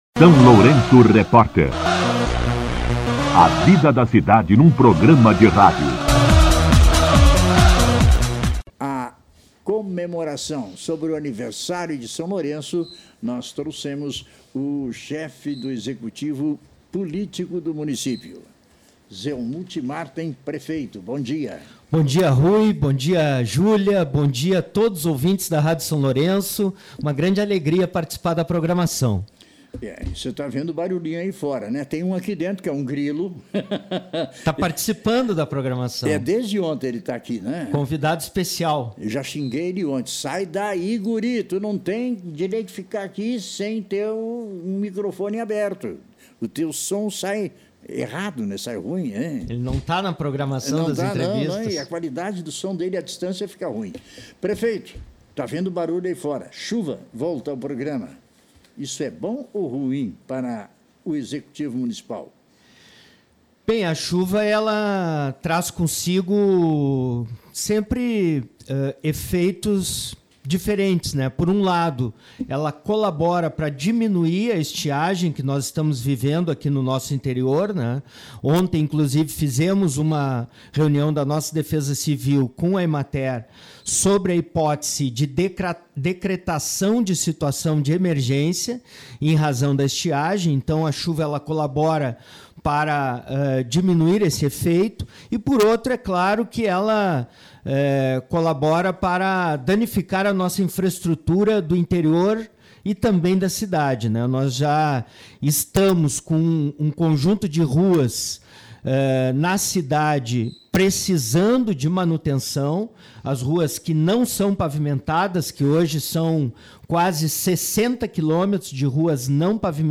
Entrevista com O prefeito Zelmute Marten
O prefeito Zelmute Marten participou do SLR RÁDIO nesta quinta-feira (16) para detalhar a programação alusiva aos 142 anos de emancipação política de São Lourenço do Sul.